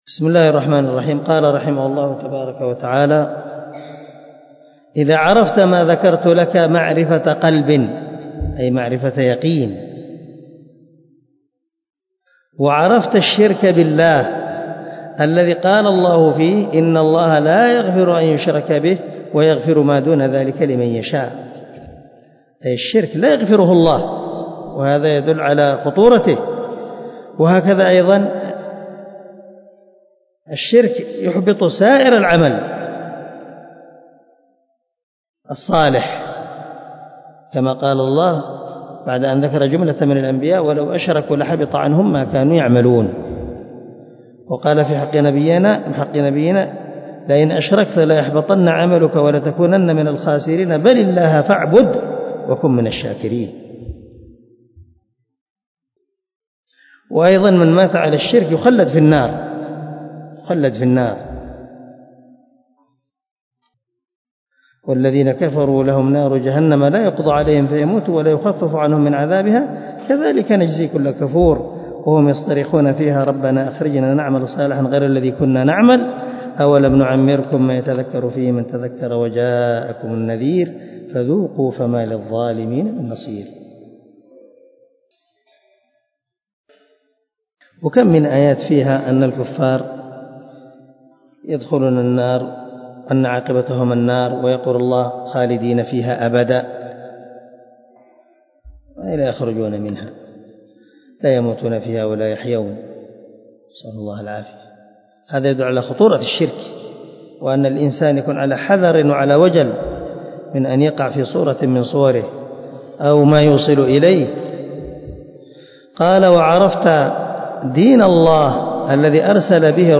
شرح كشف الشبهات 0004 الدرس 3 من شرح كتاب كشف الشبهات